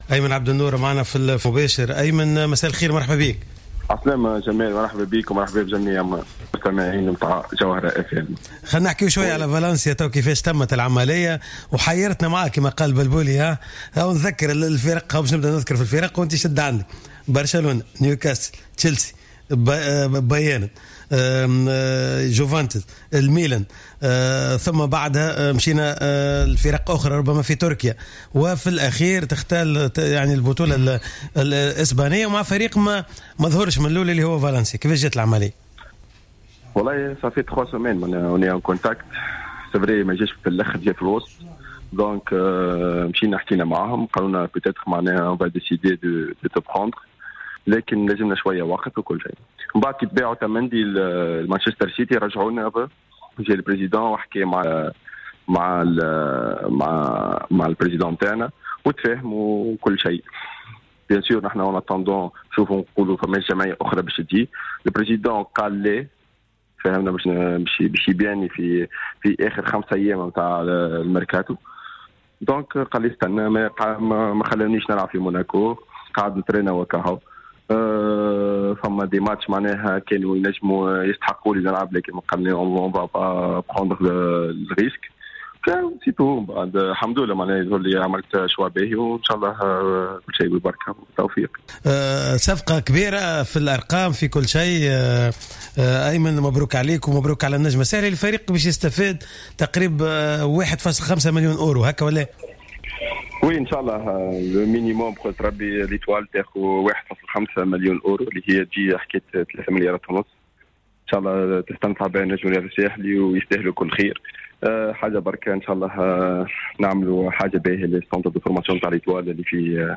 أكد الدولي التونسي أيمن عبد النور من خلال مداخلة في برنامج راديو سبور على جوهرة أف أم أنه سعيد جدا بتزامن إنضمامه لفريق فالنسيا الإسباني مع تتويج فريقه الأم النجم الرياضي الساحلي بكأس تونس لكرة القدم للمرة العاشرة في تاريخه و الثالثة على التوالي.